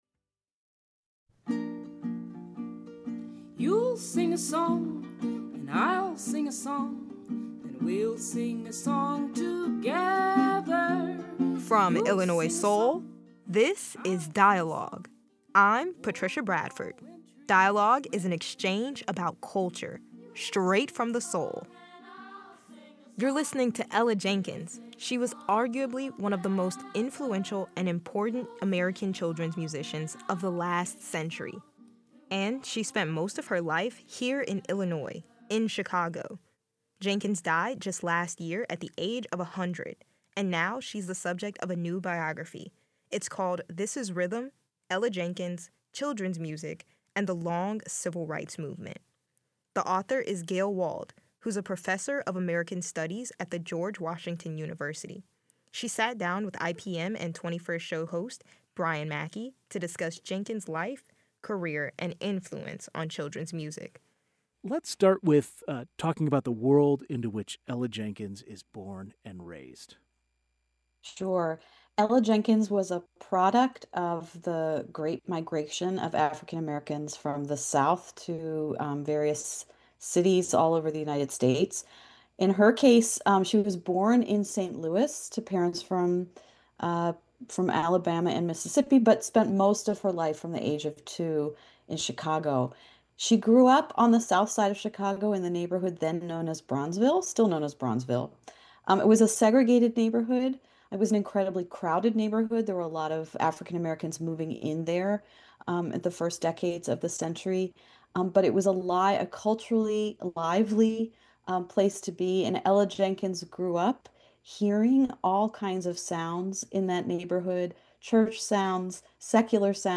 Then, NPR's Michel Martin sat down with Carol Moseley Braun to discuss her newest memoir, "Trailblazer."